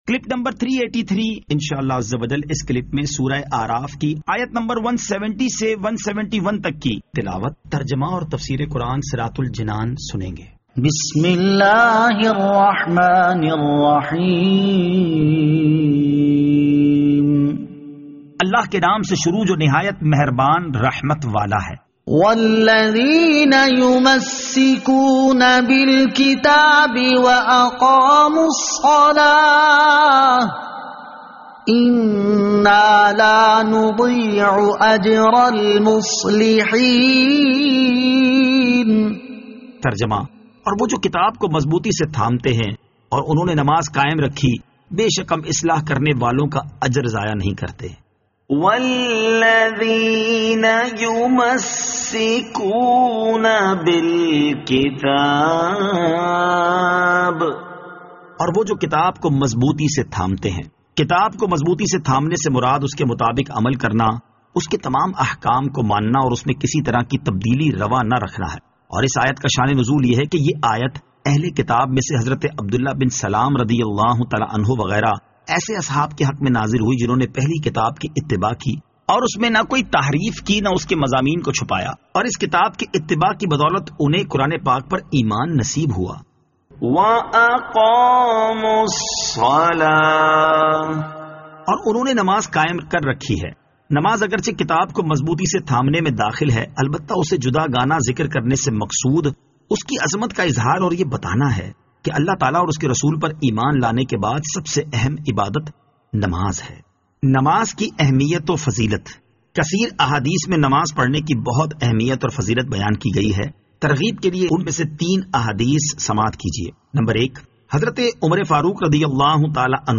Surah Al-A'raf Ayat 170 To 171 Tilawat , Tarjama , Tafseer
2021 MP3 MP4 MP4 Share سُوَّرۃُ الْاَعْرافْ آیت 170 تا 171 تلاوت ، ترجمہ ، تفسیر ۔